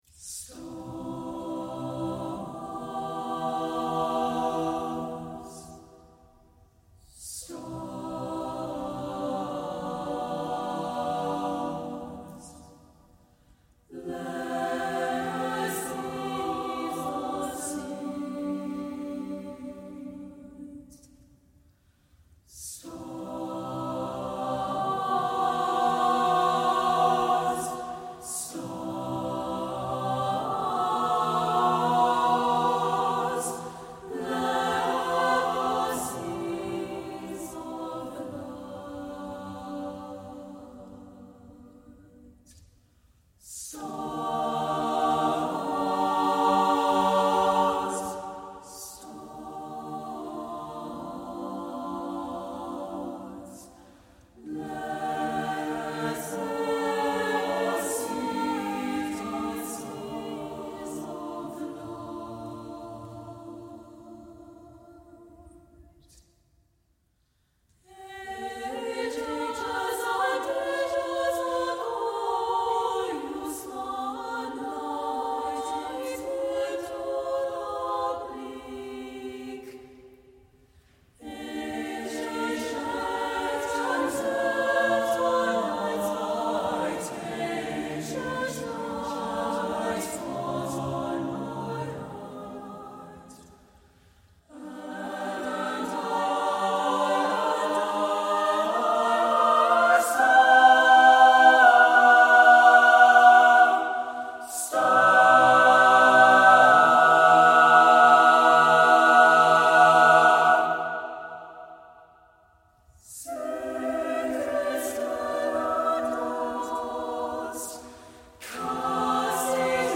• Soprano
• Alto
• Tenor
• Bass
Studio Recording
Ensemble: Mixed Chorus
Key: C major
Tempo: Shimmering rubato throughout (q. = 40)
Accompanied: A cappella